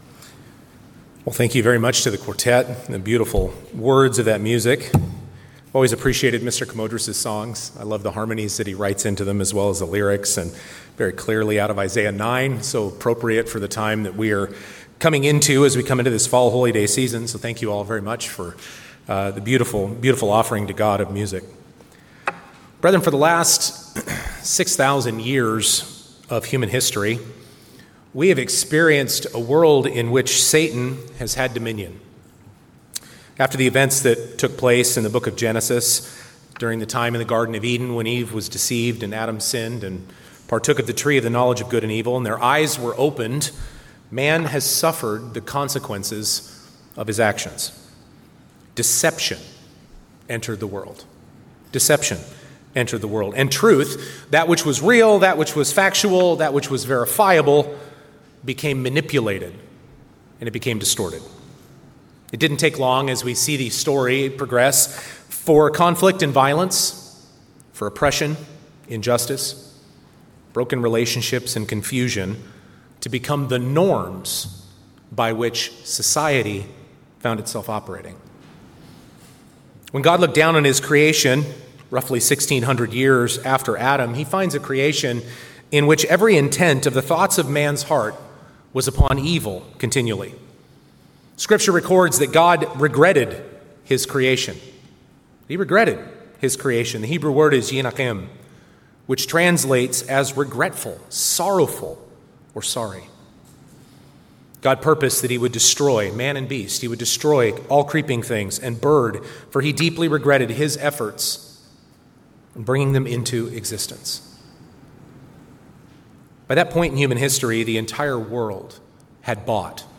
In the first of this three part series, we will examine the the prevailing human paradigm since the Fall—a world under Satan’s dominion in which deception is normalized and truth is obscured. In this sermon, we will outline the marks of the current age—violence, injustice, confusion—and contrasts them with the revealed truth (aletheia), or reality, of God’s truth.